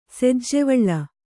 ♪ sejjevaḷḷa